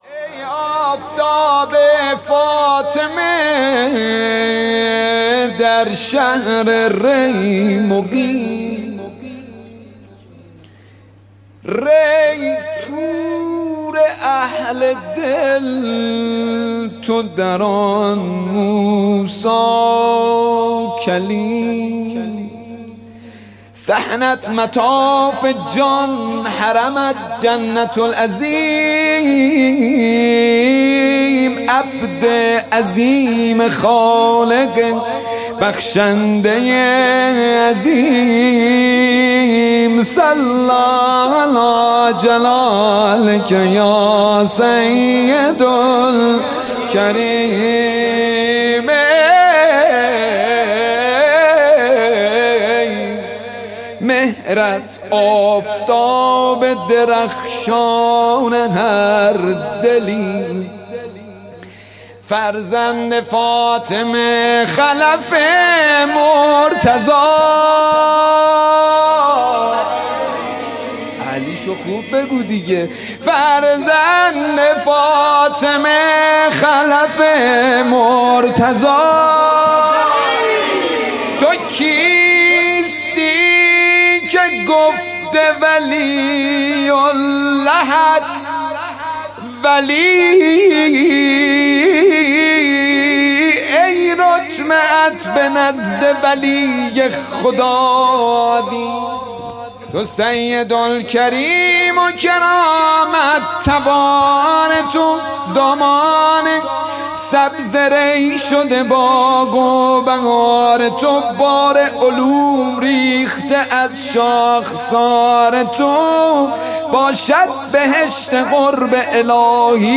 مدح